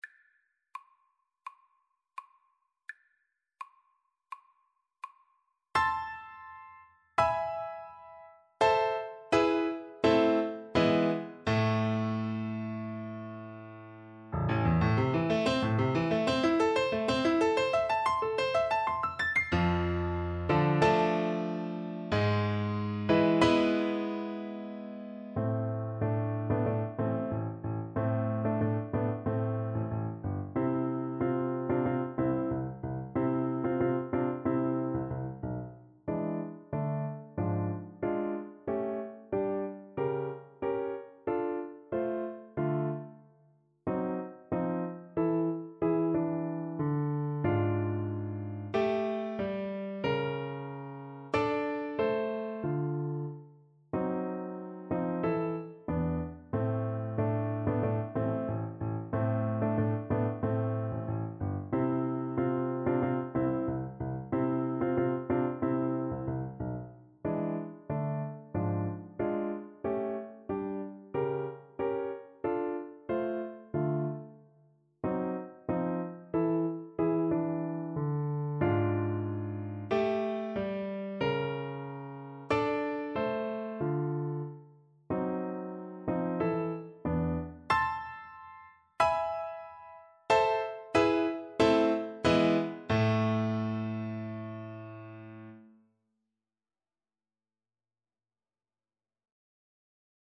Play (or use space bar on your keyboard) Pause Music Playalong - Piano Accompaniment Playalong Band Accompaniment not yet available transpose reset tempo print settings full screen
A minor (Sounding Pitch) (View more A minor Music for Cello )
Allegro molto moderato = 84 (View more music marked Allegro)
Classical (View more Classical Cello Music)